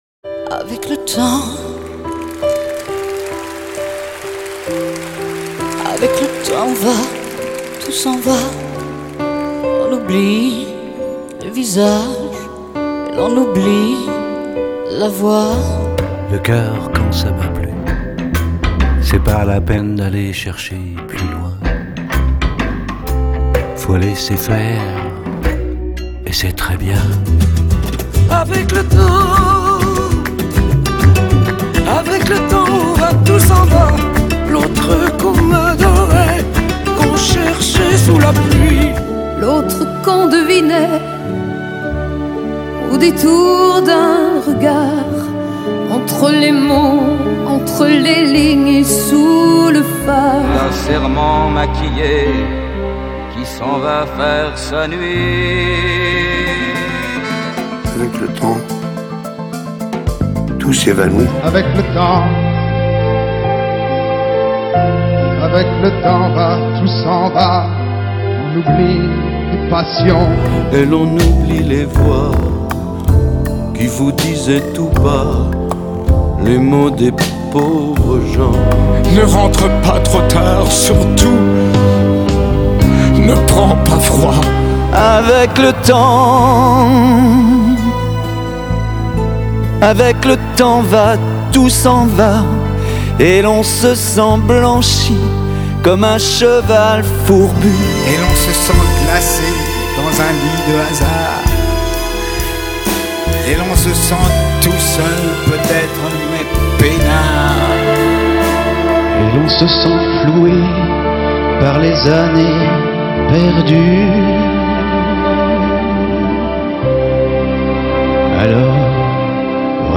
CHORALE VIRTUELLE